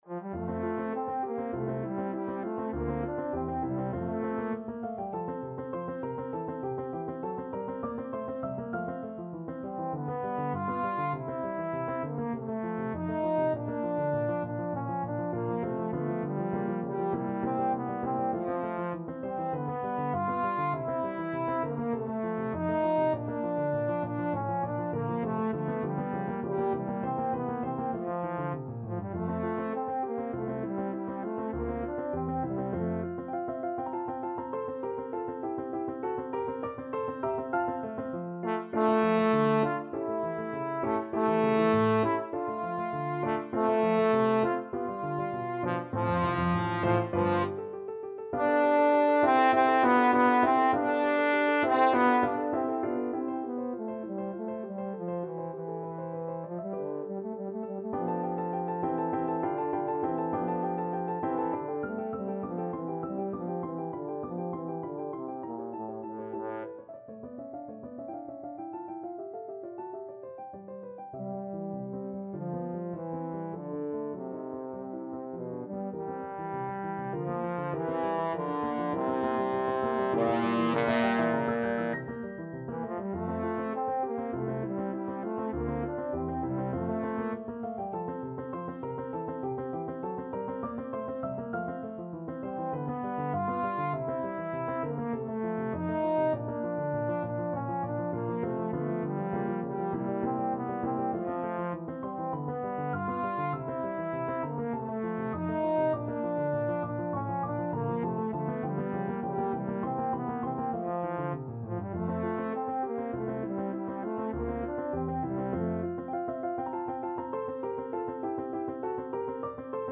Trombone version
2/4 (View more 2/4 Music)
Nicht zu geschwind und sehr singbar vorgetragen
Classical (View more Classical Trombone Music)